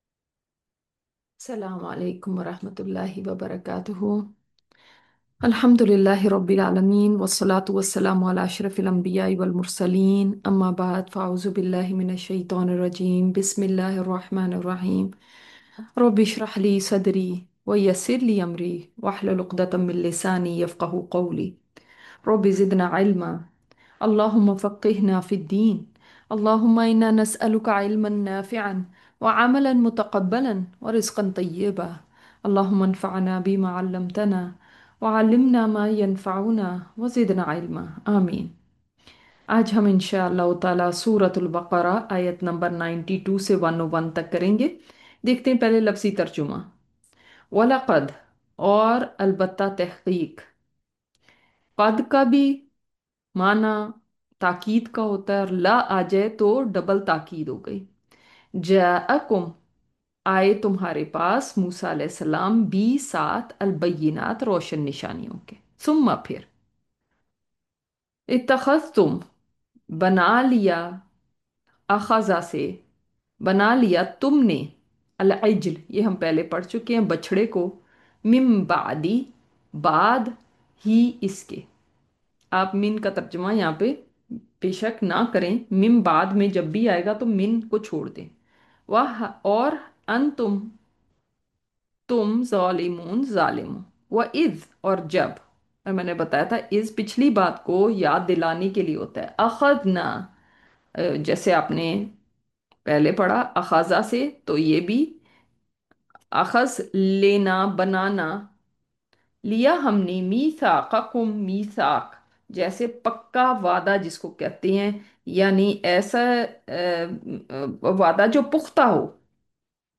Latest Lecture